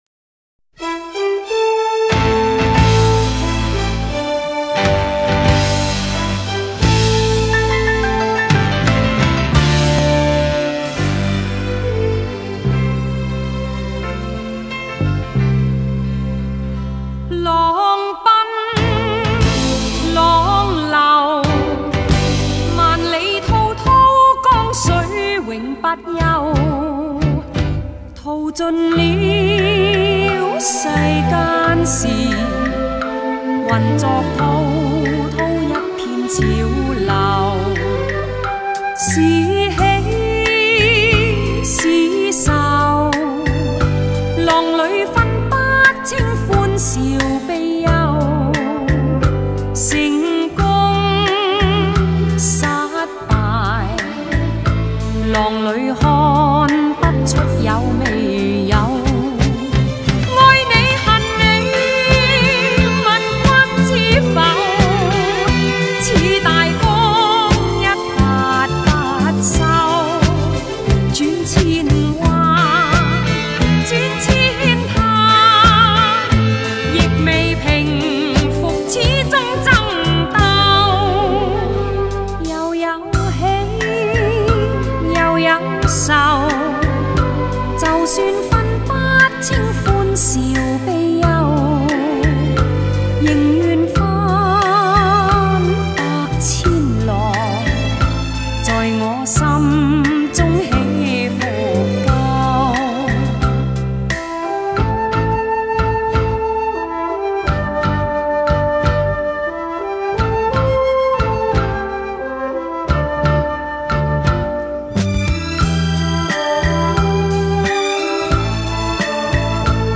Popular Chinese Song